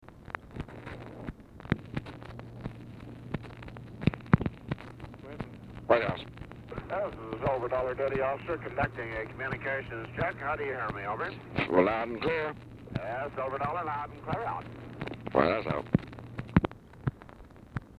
Telephone conversation # 2247, sound recording, WH COMMUNICATIONS? and SILVER DOLLAR, 2/8/1964, time unknown | Discover LBJ
UNDATED AIRCRAFT COMMUNICATION RECORDED ON BELT
Format Dictation belt